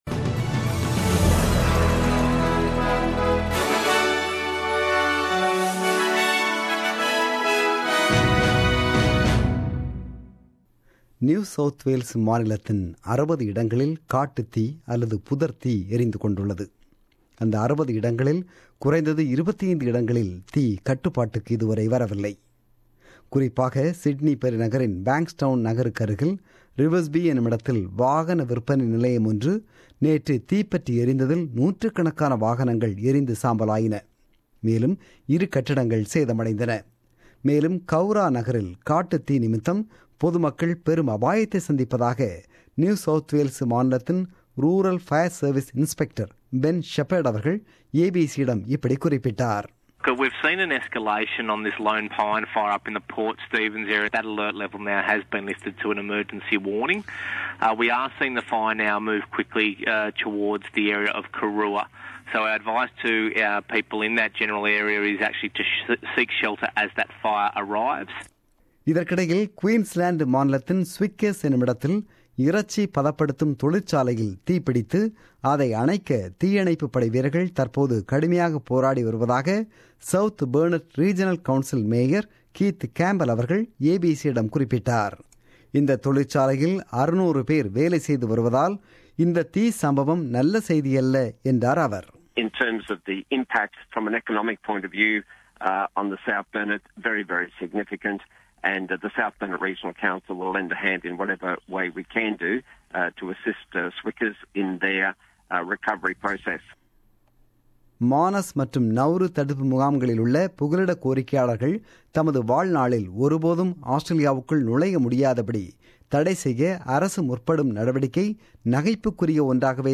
The news bulletin broadcasted on 6 Nov 2016 at 8pm.